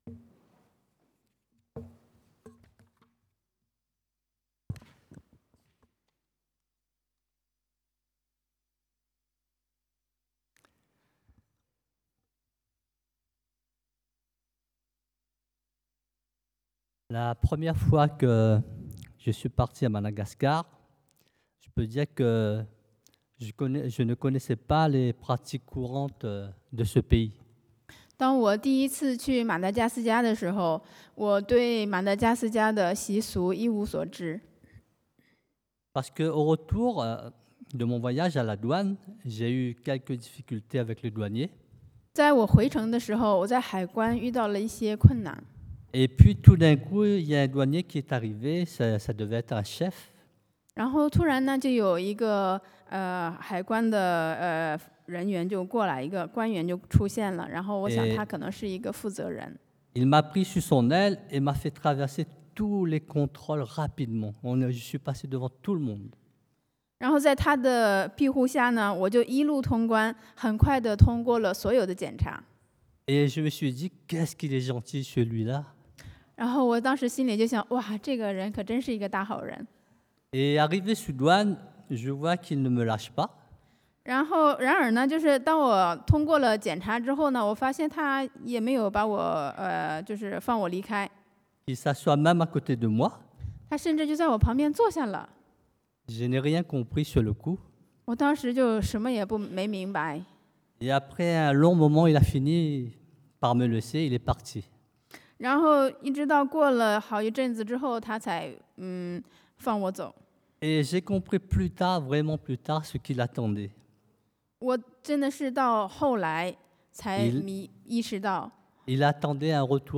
Passage: Luc 路加福音 2: 10-14 Type De Service: Predication du dimanche